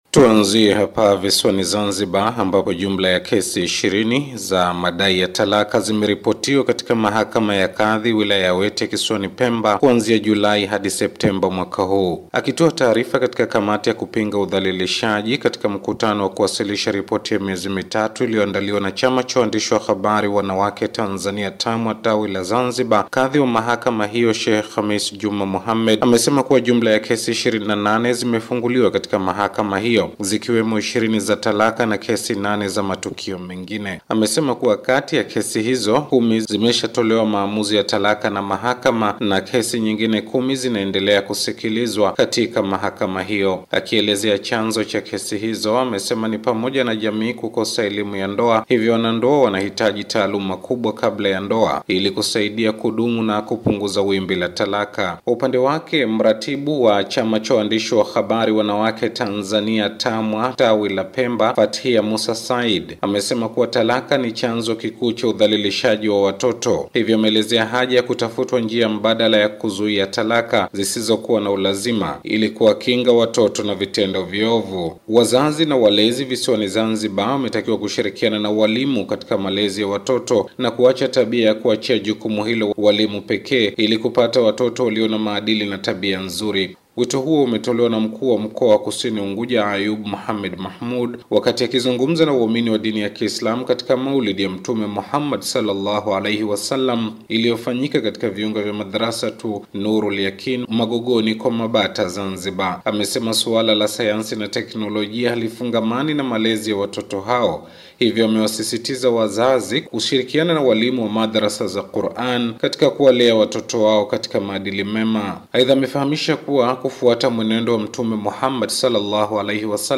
ripoti ya kila wiki ya matukio ya Kiislamu